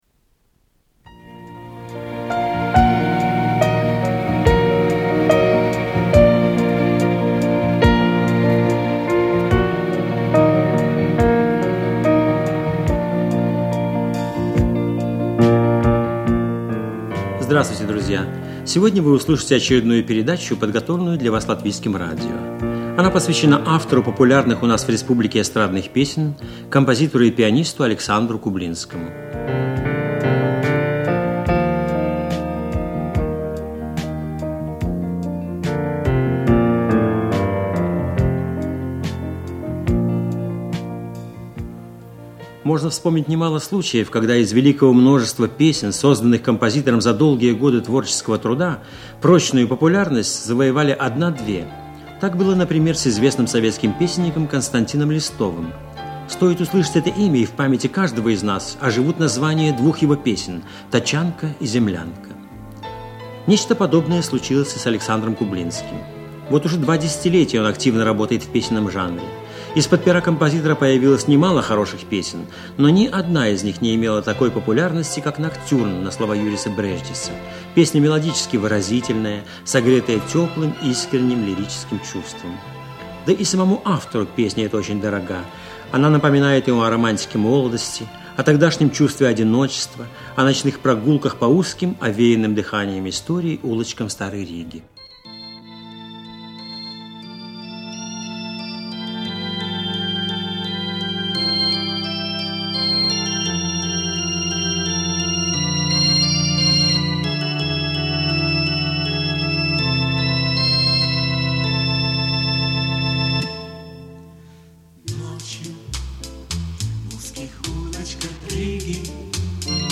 Латвийское радио. Передача о композиторе Александре Кублинском. 1983 год.